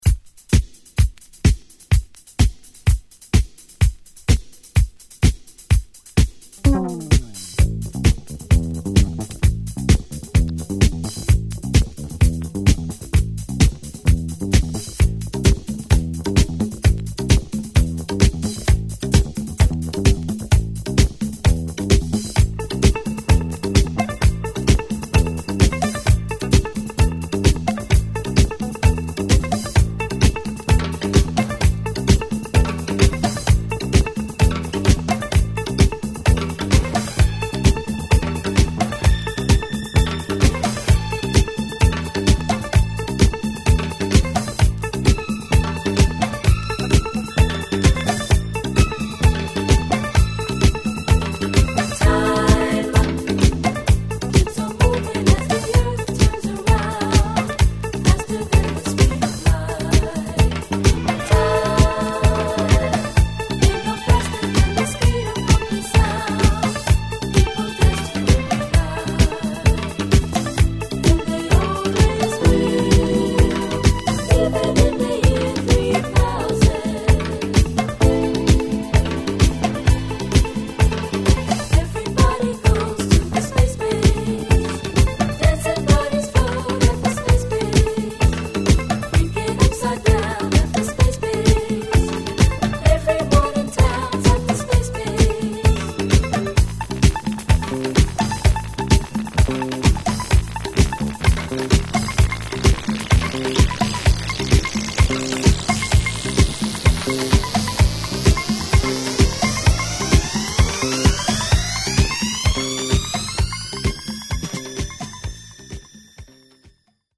-- Loft~Garage Classic�Ȥ��Ƥ��Τ���Nice Spacy Disco!!